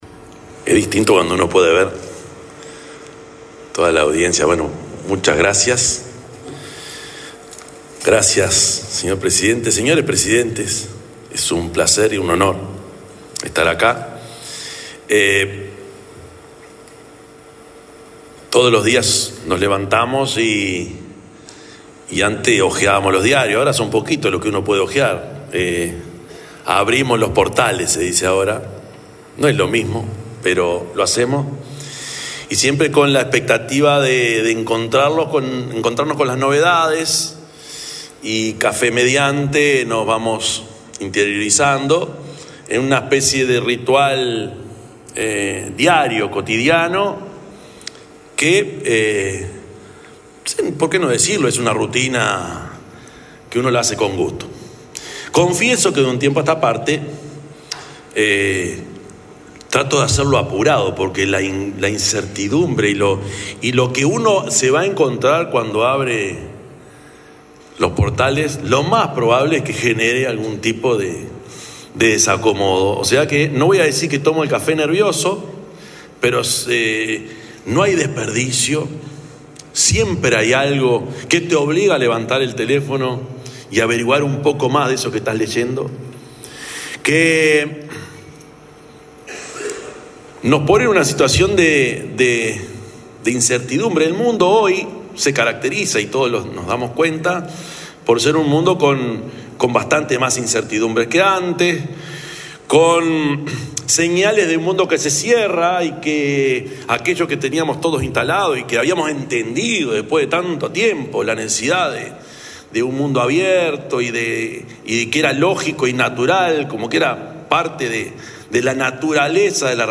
Palabras del presidente de la República, Yamandú Orsi
El presidente de la República, profesor Yamandú Orsi, participó este viernes 4, en el Latin Annual Meeting, que se realiza desde el 2 de abril en